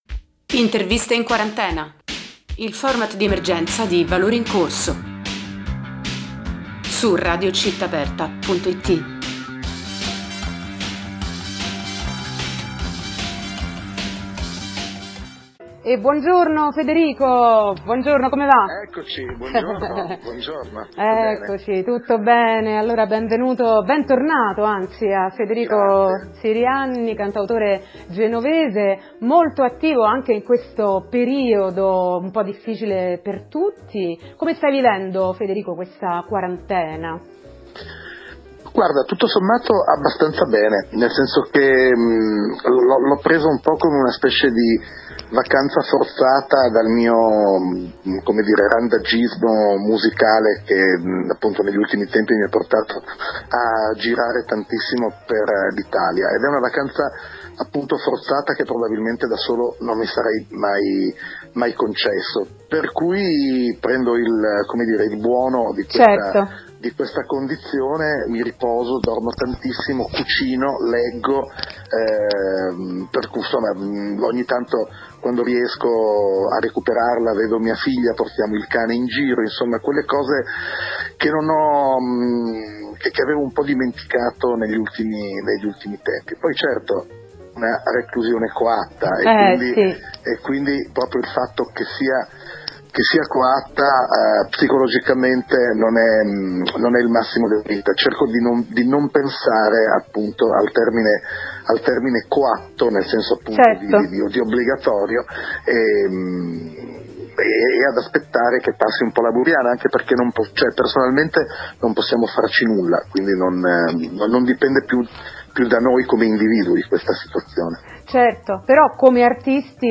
Intervista
in collegamento telefonico